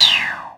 ihob/Assets/Extensions/RetroGamesSoundFX/Shoot/Shoot19.wav at master
Shoot19.wav